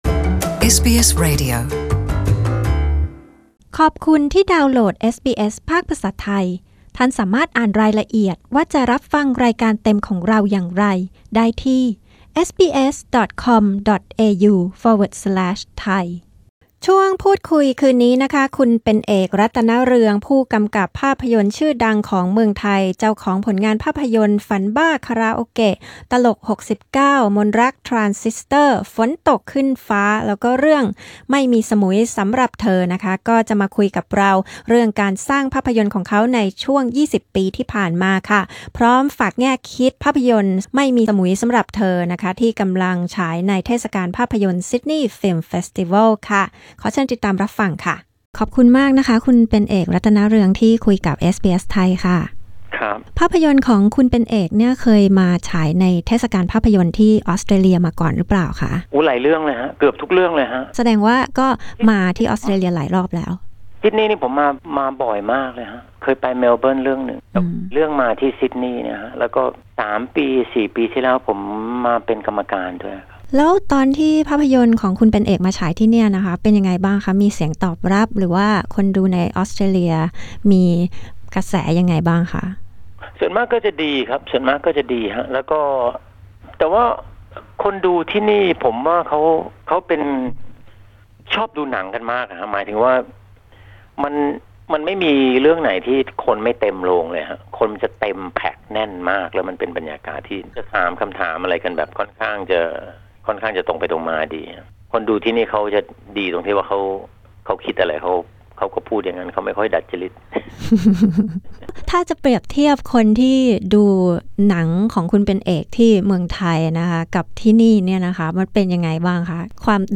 เป็นเอก รัตนเรือง ผู้กำกับภาพยนตร์คนดัง คุยเรื่องการสร้างภาพยนตร์ไทยนอกกระแส และแนวคิดเบื้องหลัง ‘Samui Song’ ภาพยนตร์เรื่องล่าสุดของเขา